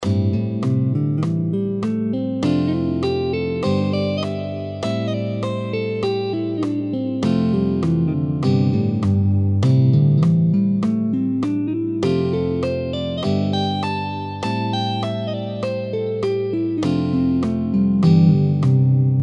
さて続いては指板をワイドに使用しペンタとニックスケールを弾いてみましょう！
音源　penta tonic scale wide position
penta_tonic_wide_position.mp3